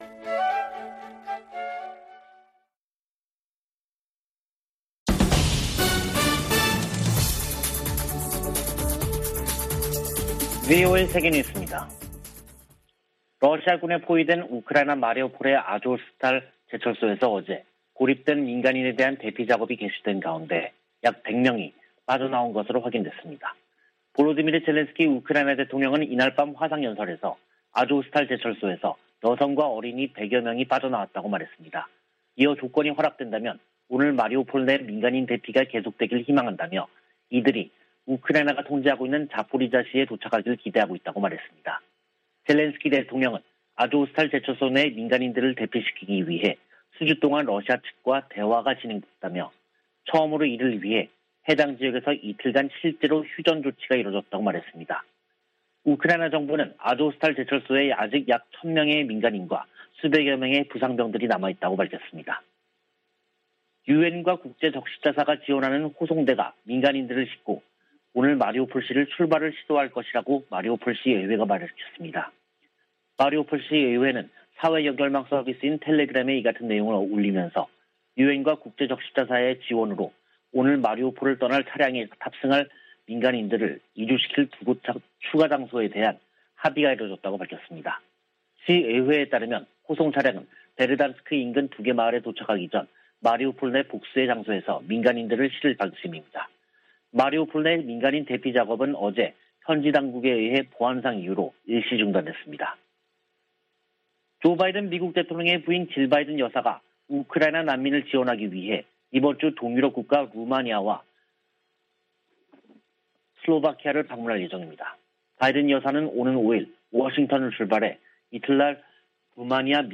VOA 한국어 간판 뉴스 프로그램 '뉴스 투데이', 2022년 5월 2일 3부 방송입니다. 미 국무부가 핵실험 준비 동향이 포착된 북한에 대해 역내에 심각한 불안정을 초래한다고 지적하고 대화를 촉구했습니다. 북한 풍계리 핵실험장 3번 갱도 내부와 새 입구 주변에서 공사가 활발히 진행 중이라는 위성사진 분석이 나왔습니다. 빌 해거티 미 상원의원은 조 바이든 대통령의 한일 순방이 인도태평양 지역 적국들에 중요한 신호를 보낼 것이라고 밝혔습니다.